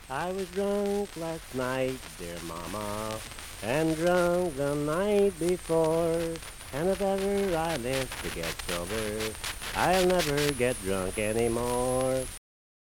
Unaccompanied vocal music
Verse-refrain 1(4).
Performed in Hundred, Wetzel County, WV.
Voice (sung)